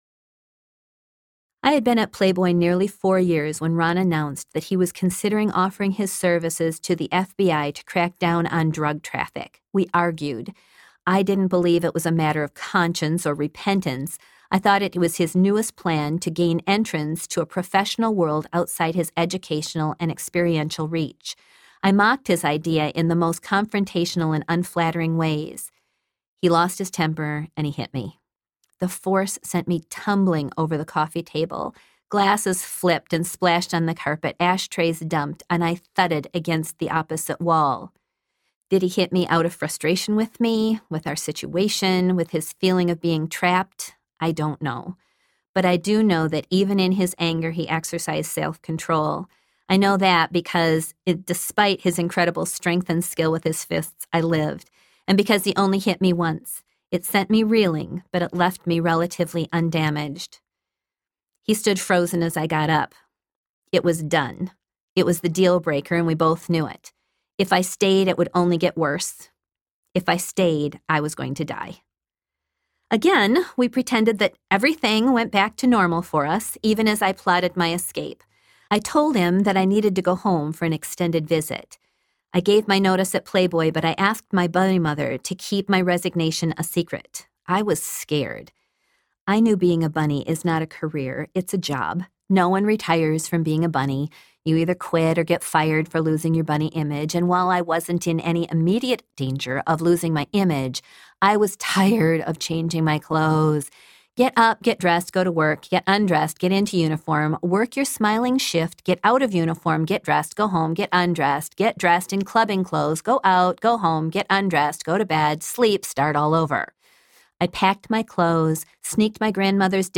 The Widow Wore Pink Audiobook
6.68 Hrs. – Unabridged